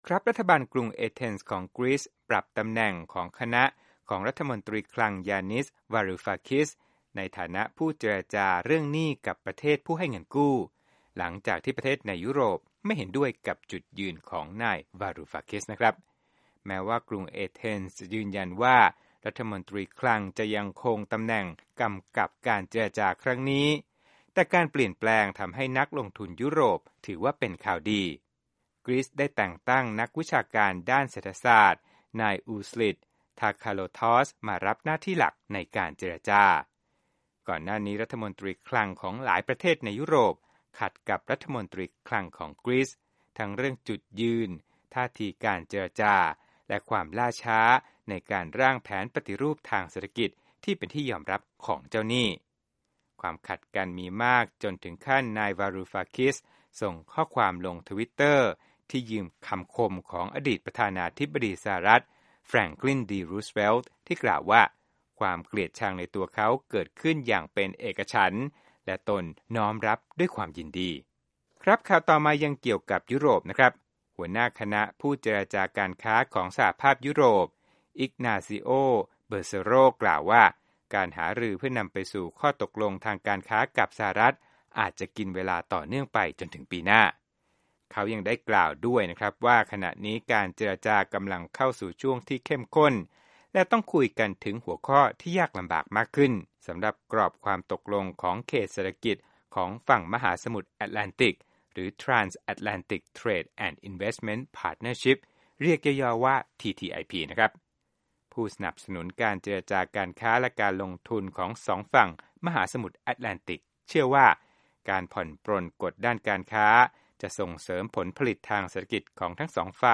รวมข่าวธุรกิจ 4/28/15